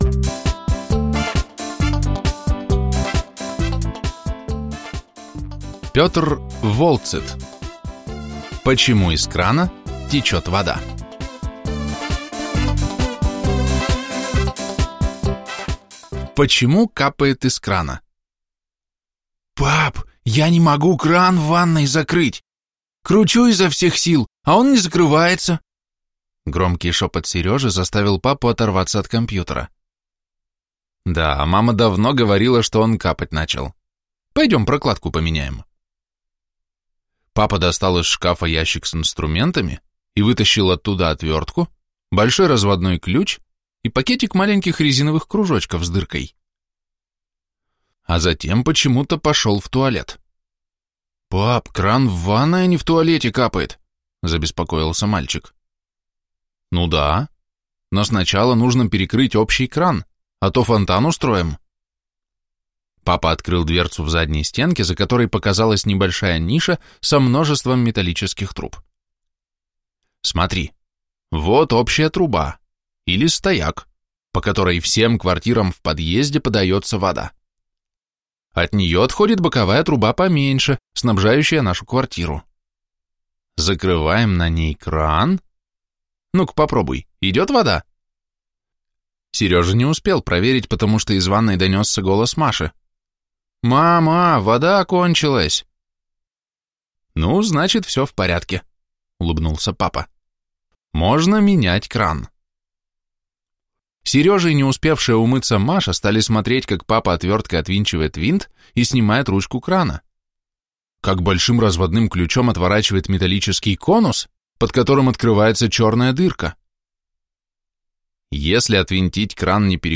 Аудиокнига Почему из крана вода течет?